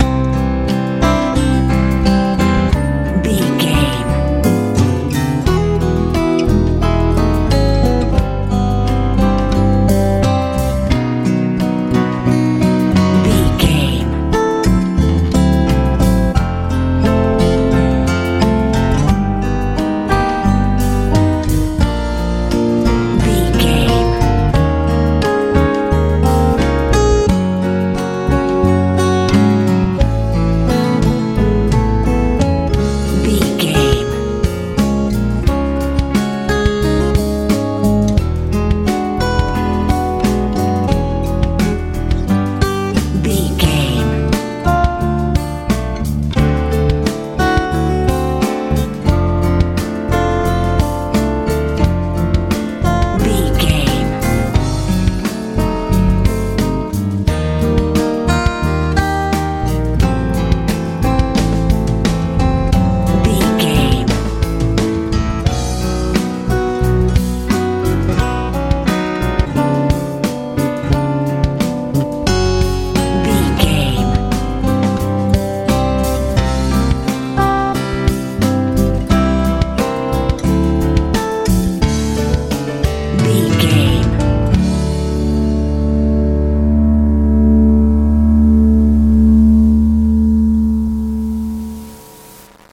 pop ballad feel
Ionian/Major
light
mellow
piano
acoustic guitar
drums
bass guitar
calm
relaxed